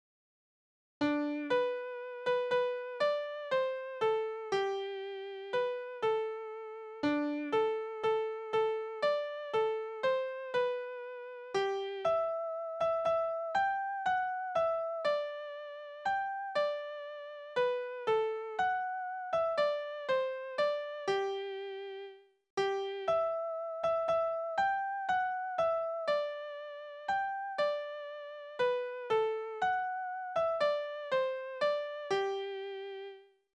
Ich habe den Frühling gesehen Naturlieder Tonart: G-Dur Taktart: 3/4 Tonumfang: Oktave, Quarte Besetzung: vokal